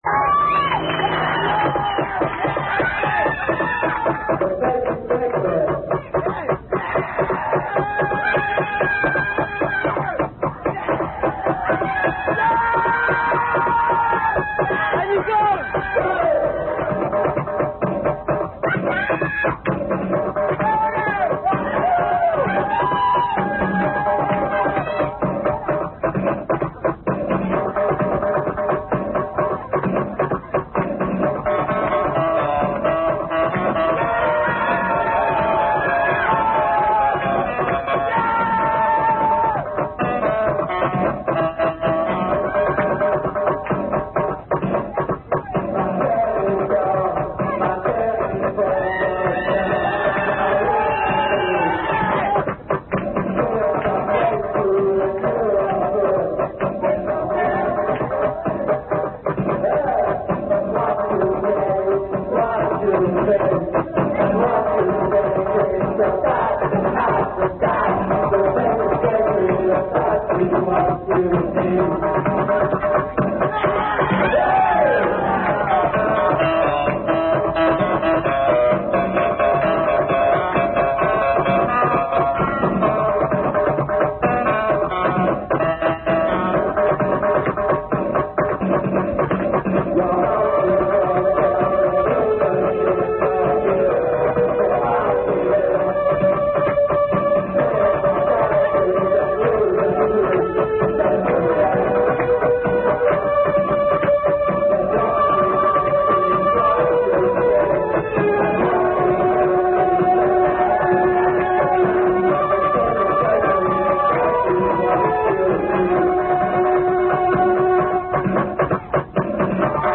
9.05.91.- France, Dunkerque